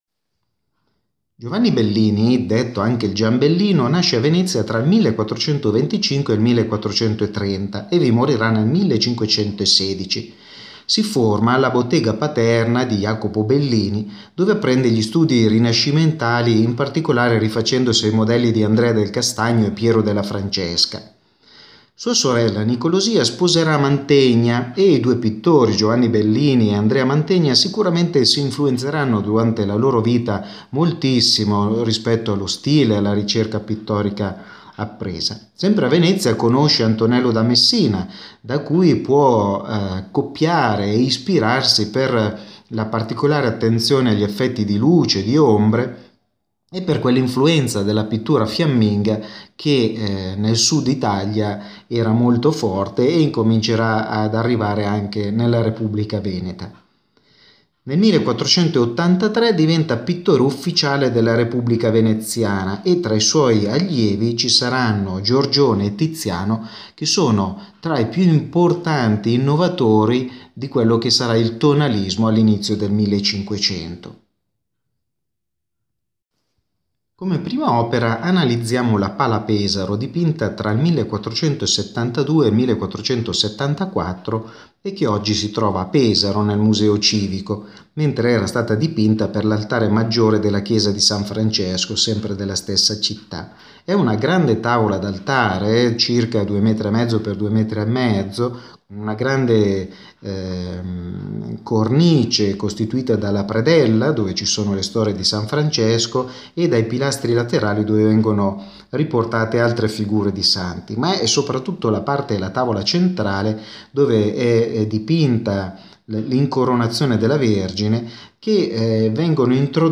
Ascolta la lezione audio dedicata a Giovanni Bellini